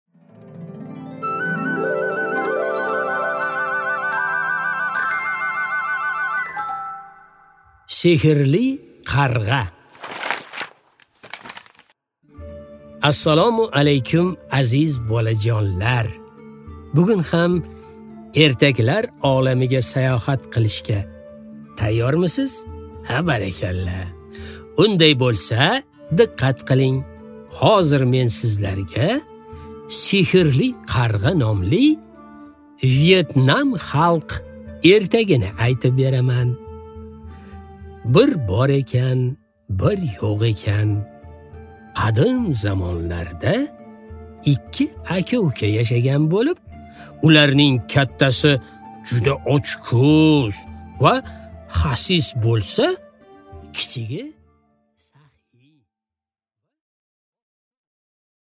Аудиокнига Sehrli qarg'a